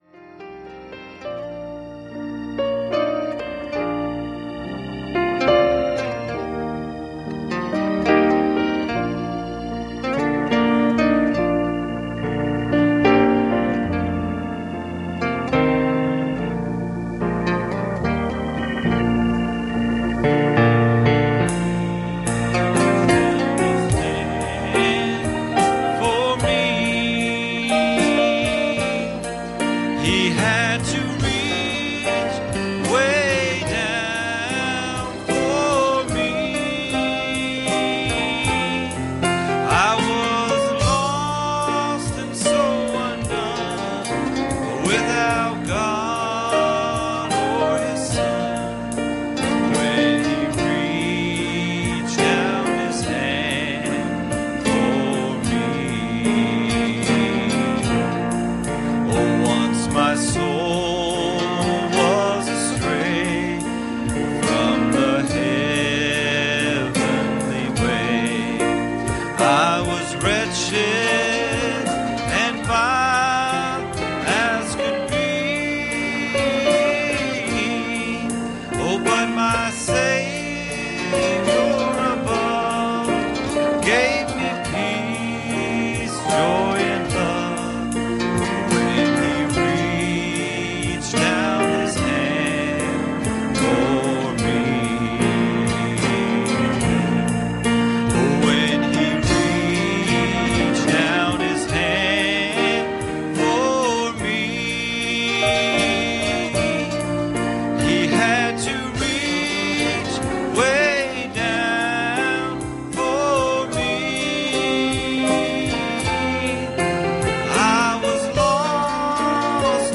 Passage: 2 Kings 23:24 Service Type: Sunday Morning